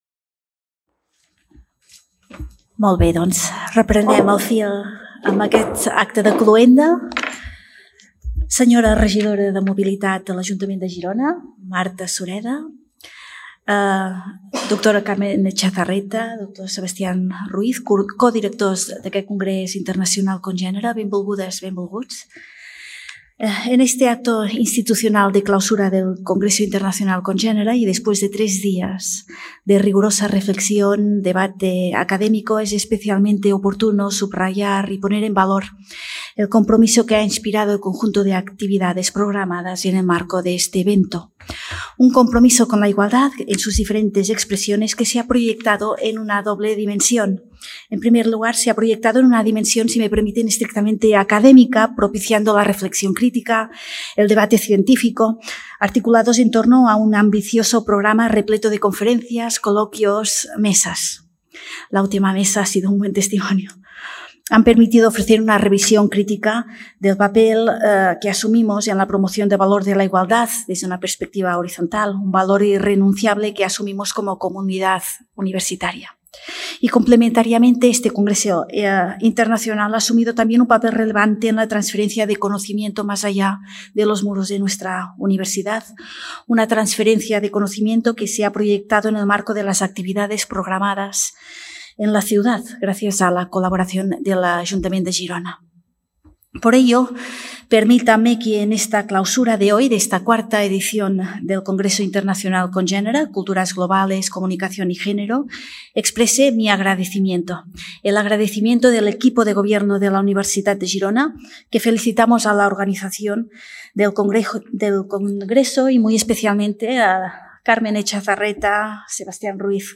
Acto protocolario de clausura y cierre
Acte de cloenda del 4t Congrés Internacional Congenere en el qual es posa en valor el compromís amb la igualtat sorgit de les reflexions de les diferents conferències i taules rodones.